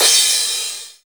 DJP_PERC_ (2).wav